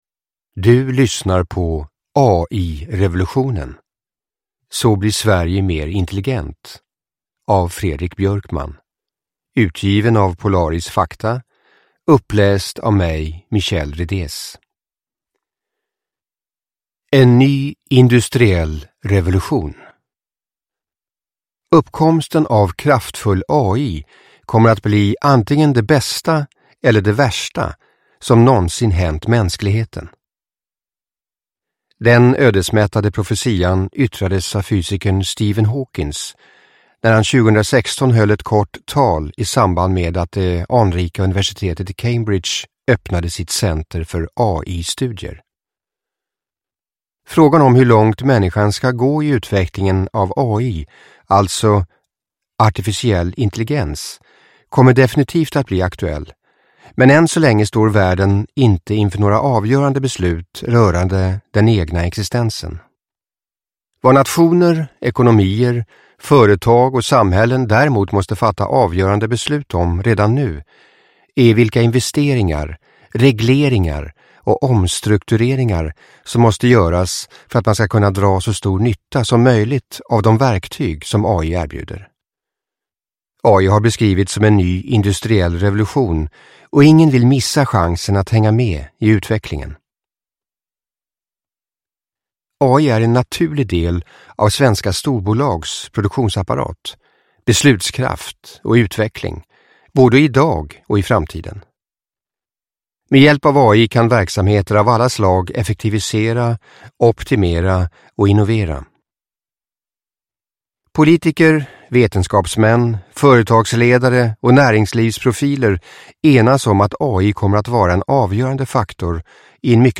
AI-revolutionen: så blir Sverige mer intelligent – Ljudbok – Laddas ner